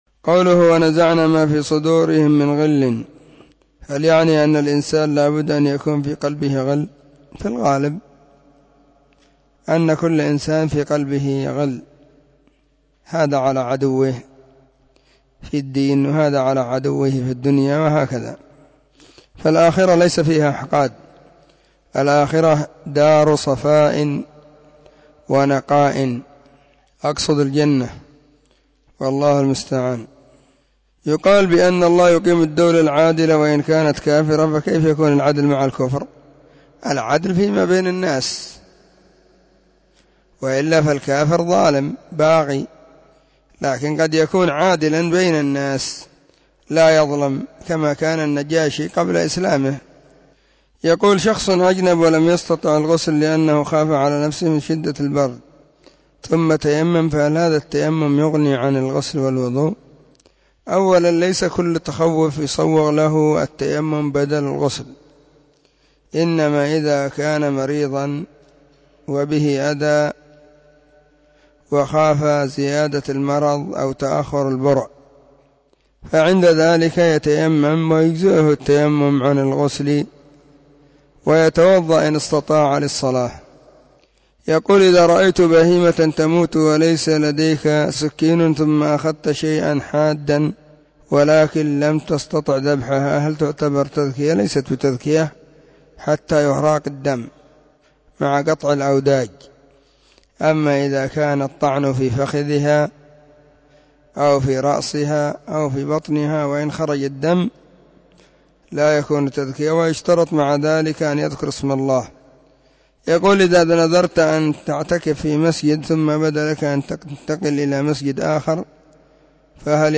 فتاوى الأحد 2 /ربيع الثاني/ 1443 هجرية. ⭕ أسئلة ⭕ -7